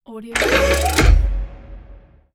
Upgraded_Cannon_1.mp3